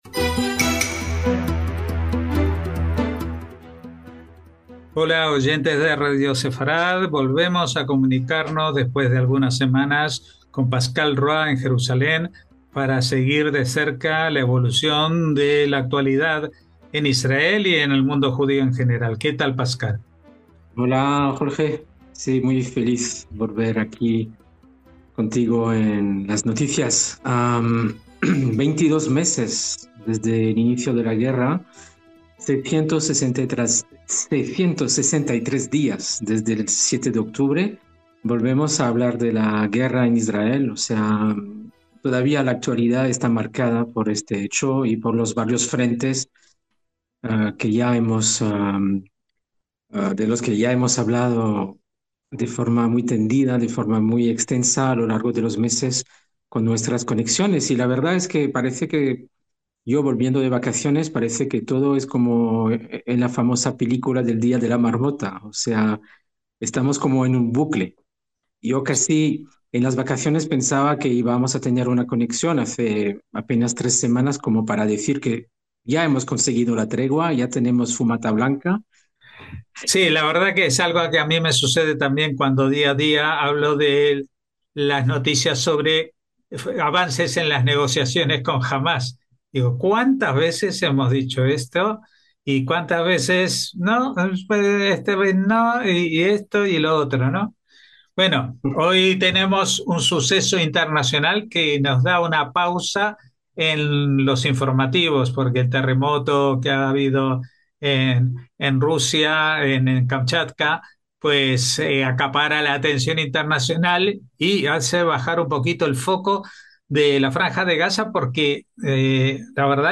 NOTICIAS CON COMENTARIO A DOS